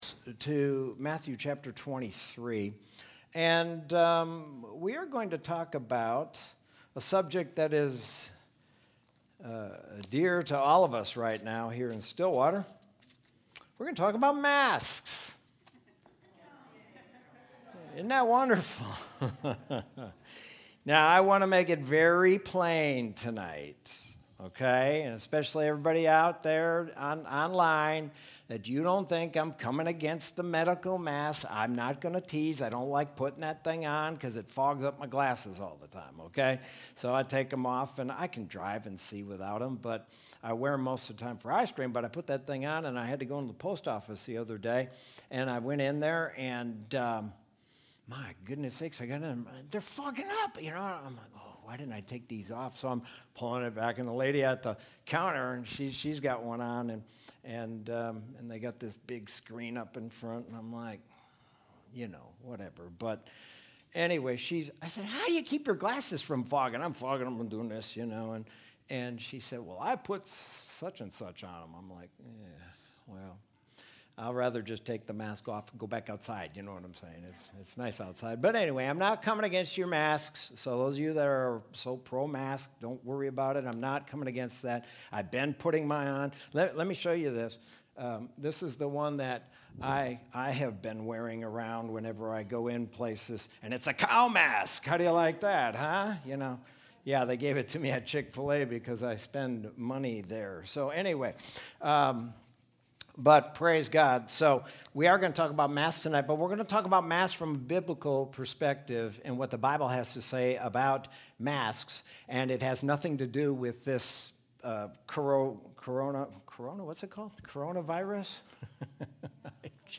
Sermon from Wednesday, July 29, 2020.